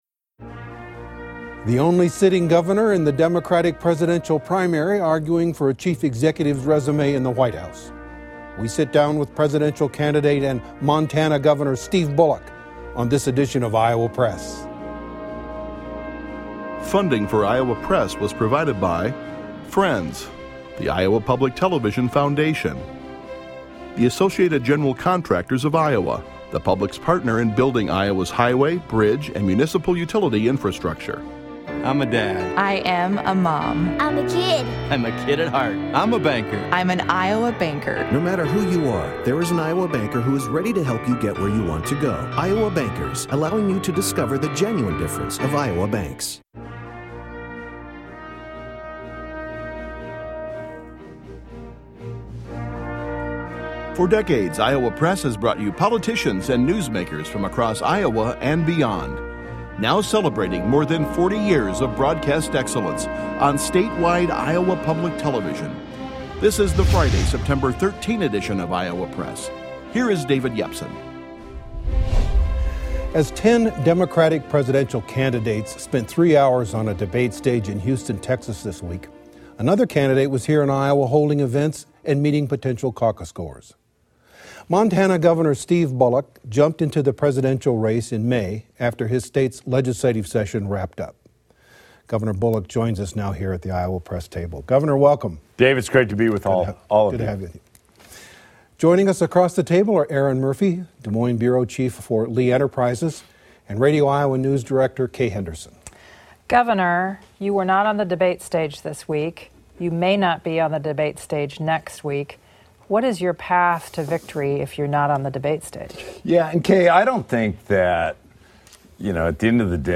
The only sitting Governor in the democratic presidential primary arguing for a chief executive's resume in the White House. We sit down with presidential candidate and Montana Governor Steve Bullock on this edition of Iowa Press.